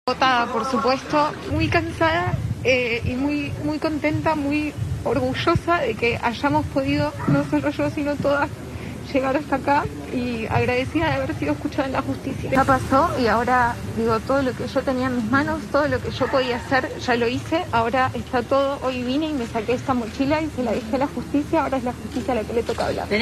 Al terminar de declarar de manera virtual durante cuatro horas en la primera audiencia del juicio oral que se lleva adelante en Brasil contra Juan Darthés por violación agravada, un delito que tiene penas de 8 a 12 años en el código penal del país vecino, Thelma Fardin habló con los medios y reconoció que está agotada.
Se la veía cansada y por momentos tan  conmovida que se le hacía difícil poder hablar.